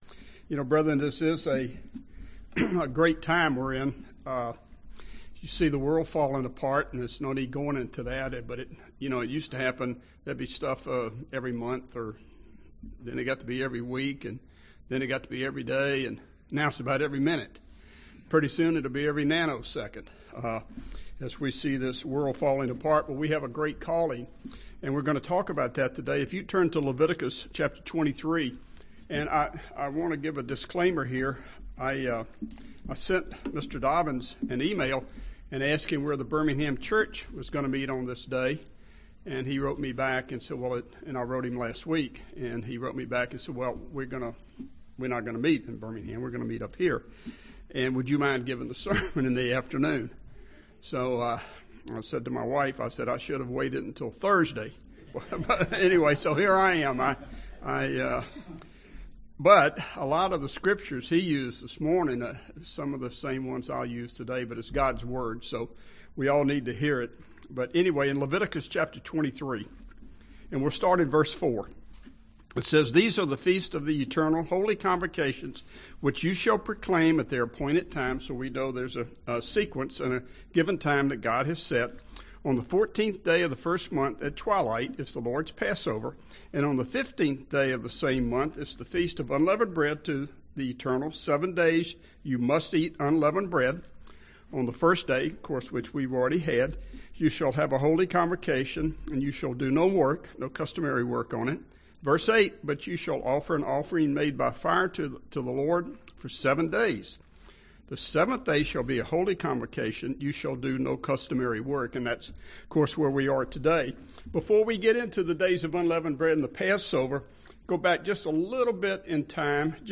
This sermon shows us that as we journey toward eternal life we desperately need the help of our Heavenly Father and Jesus Christ. Passover opens the door to eternal life. The Holy Days keep us on the correct path.